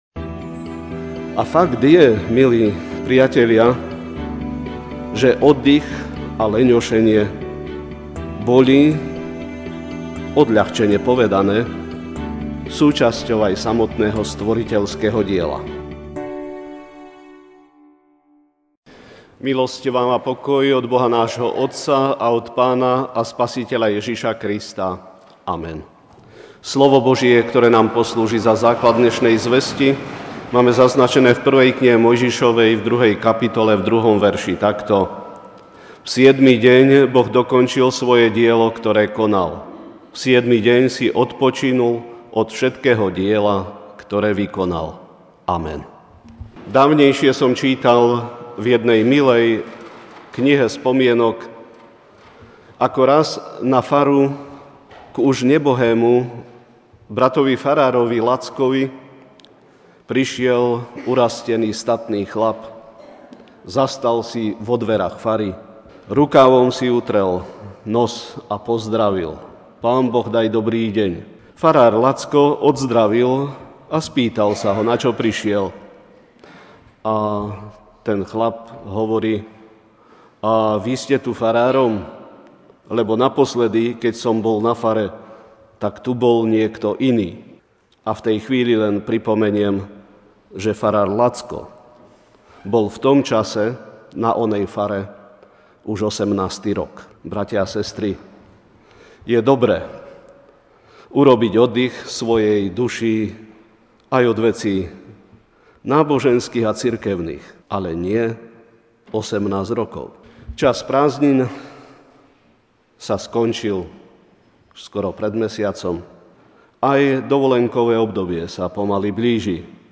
Ranná kázeň: Odpočinok duše (1Mojžišova 2, 2)Na siedmy deň Boh dokončil svoje dielo, ktoré konal: na siedmy deň si odpočinul od všetkého diela, ktoré vykonal.